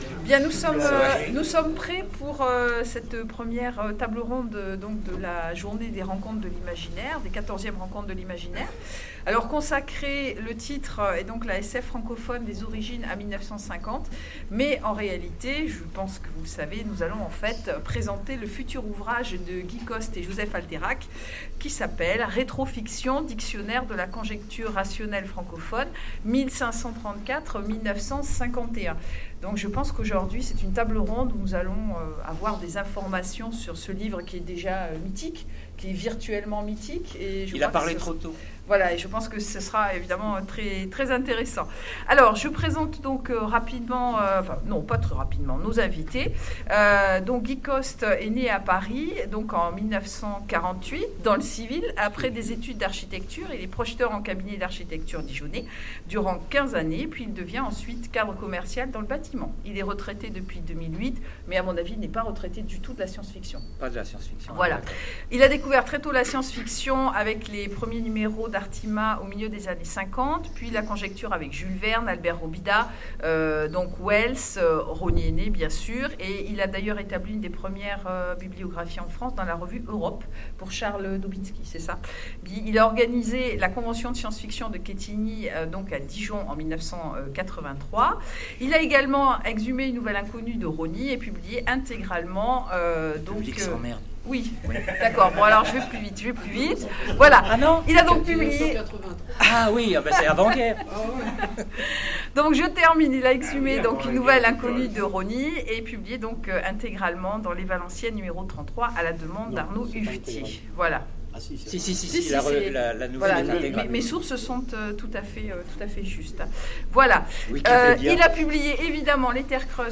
Rencontres de l'Imaginaire 2017: Conférence la SF francophone des origines à 1950